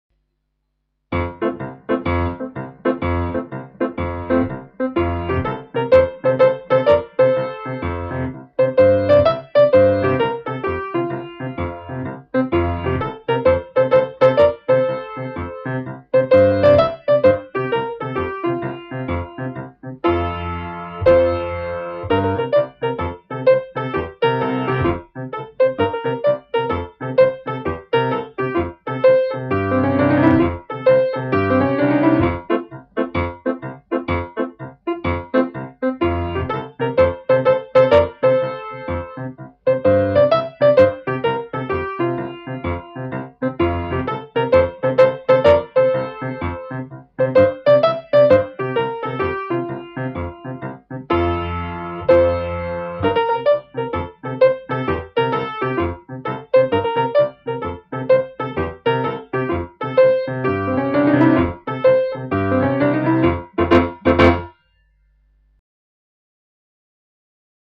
Trescone - musique
Description - TRESCONE (chanson et danse populaire de Toscana)
Trescone_musica.mp3